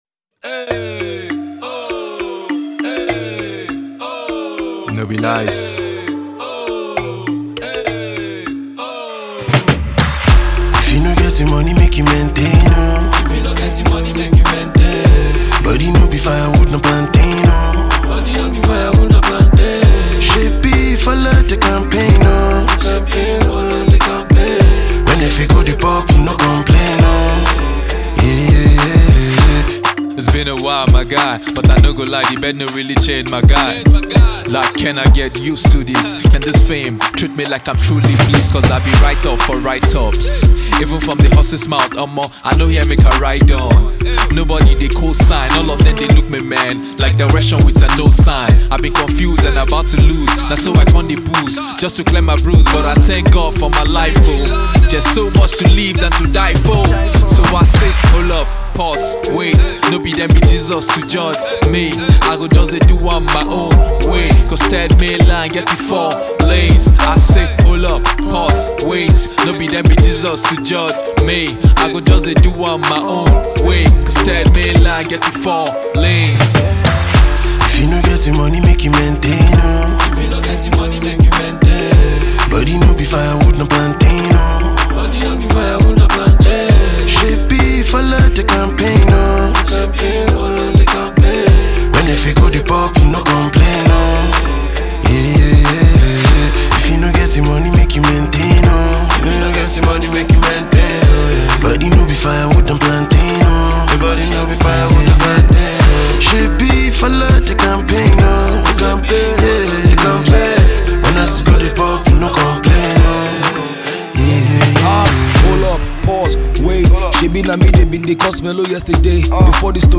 alté music